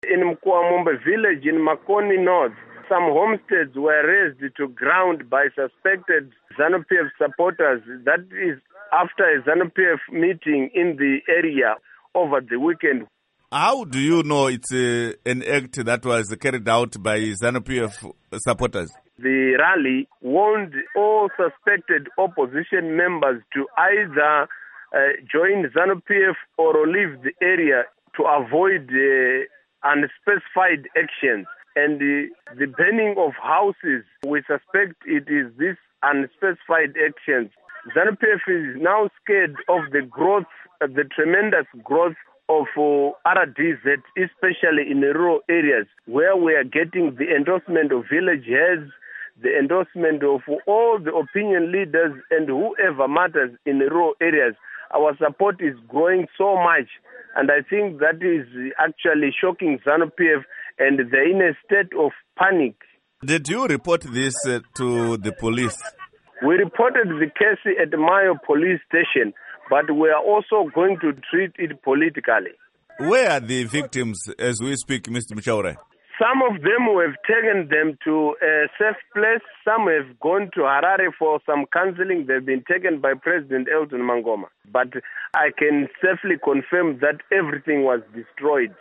Interview With Pishai Muchauraya